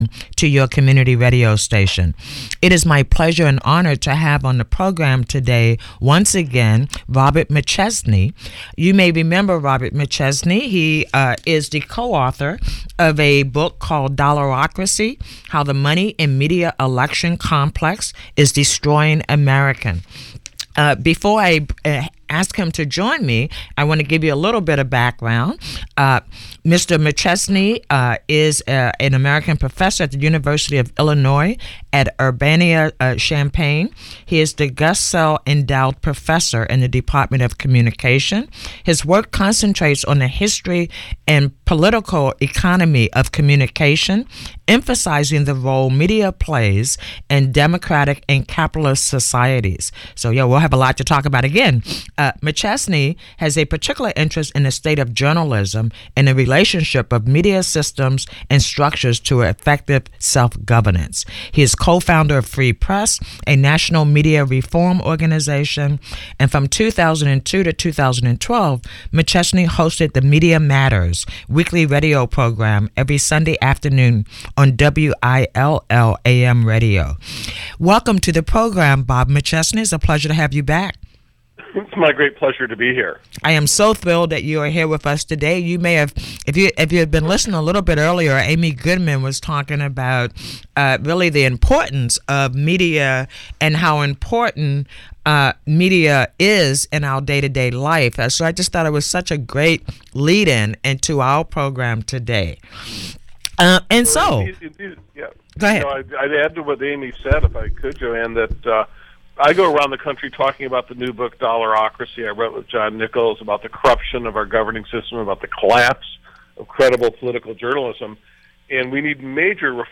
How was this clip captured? With an hour to invest, the call-in format engages listeners in meaningful conversations about crucial issues like racial disparity, government accountability, environmental justice and politics on local, state and national levels.